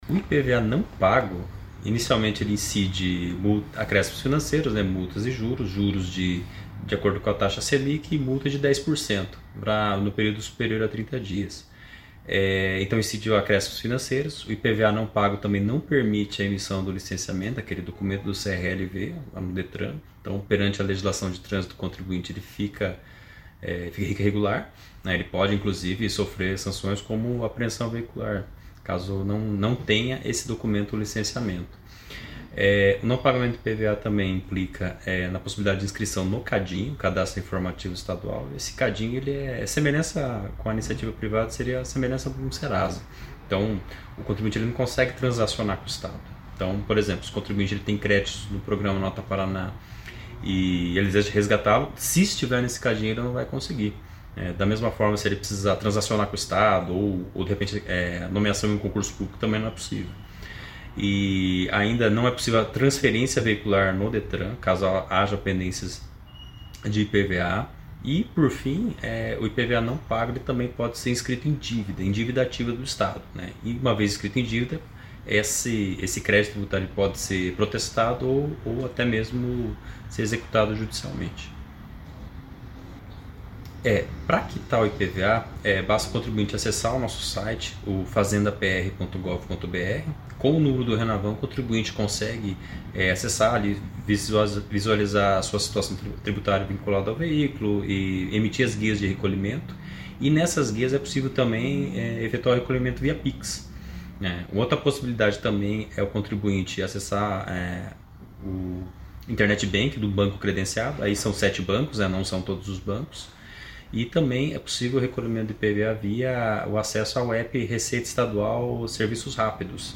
Sonora do chefe do Setor de Imposto Sobre a Propriedade de Veículos Automotores